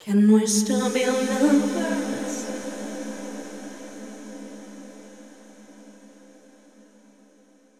Réverbération
voix_flanger+reverb+stereo.wav